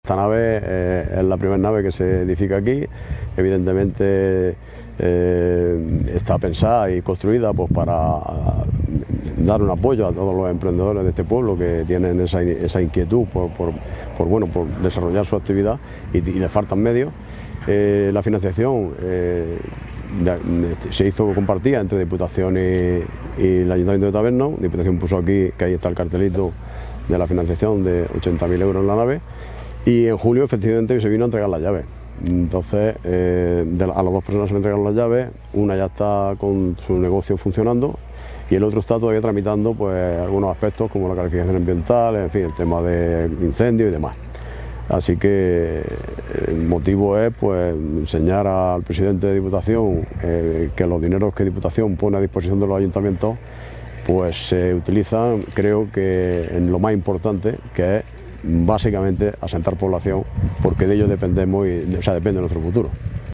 AUDIO-ALCALDE-DE-TABERNO-1.mp3